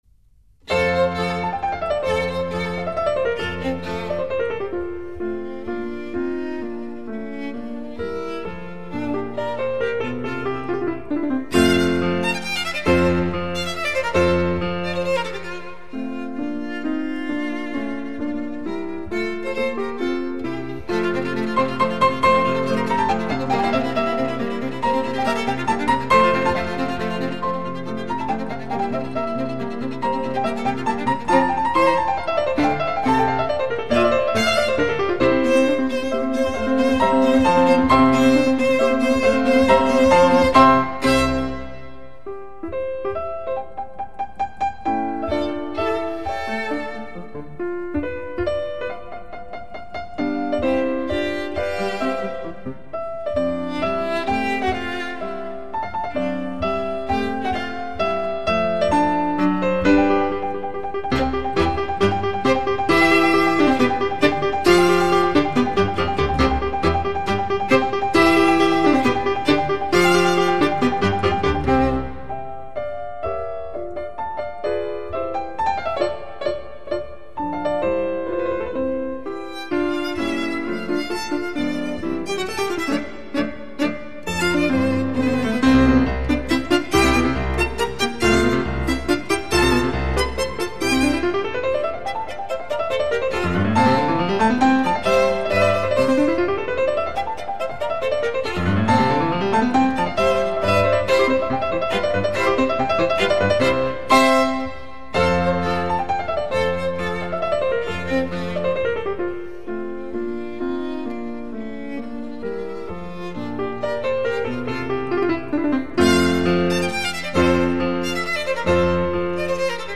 《小提琴奏鸣曲/弦乐二重奏和三重奏(9CD)》